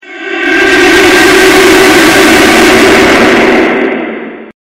Download Smash Bros sound effect for free.